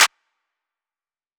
metro snare clap.wav